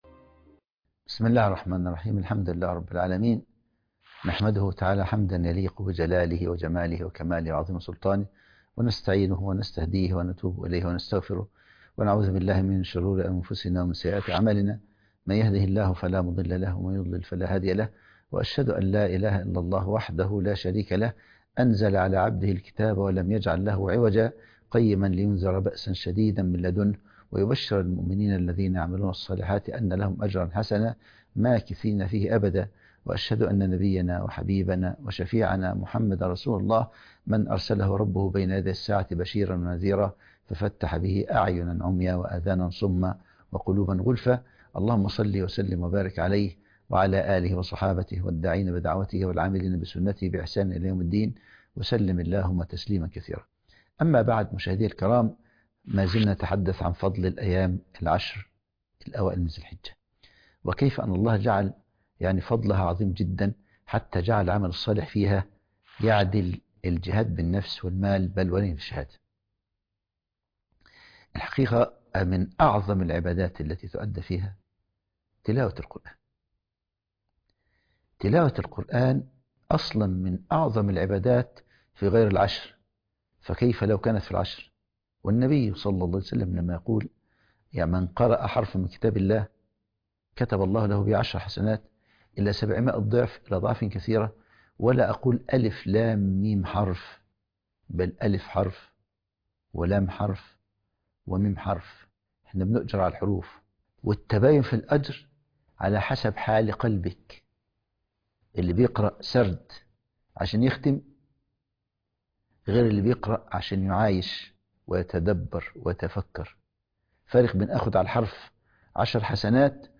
الدرس (5) نفحات العشر